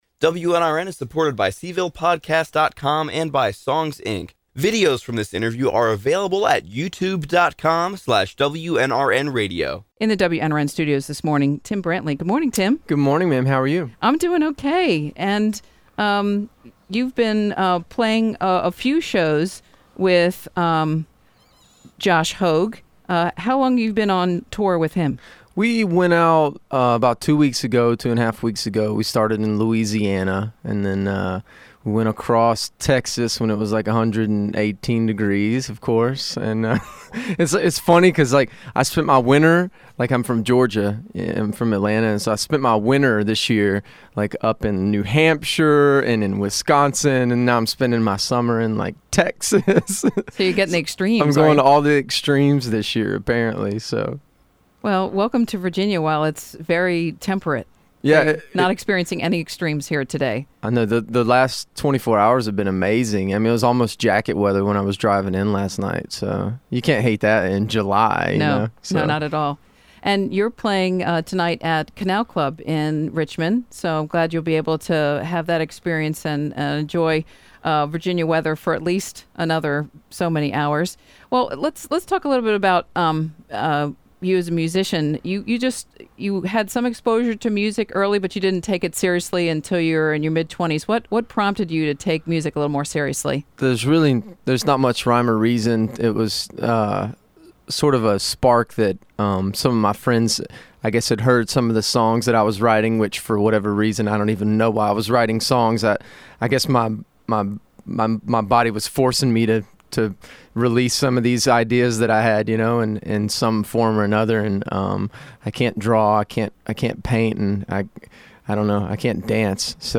Singer/Songwriter/Pianist